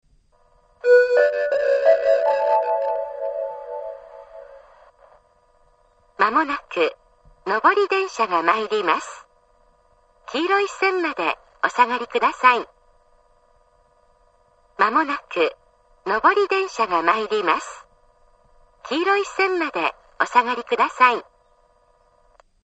１番線接近放送